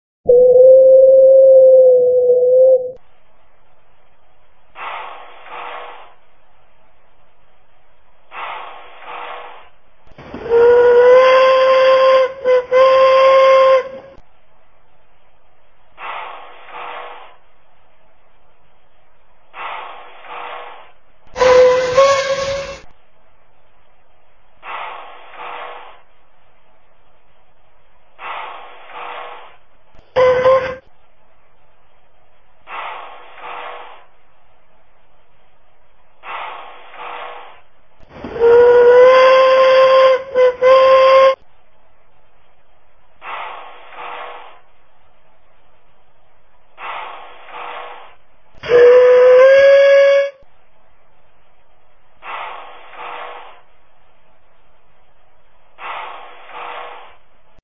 Puffing Billy NA with Brake Pump
whistles_na_and_pump.mp3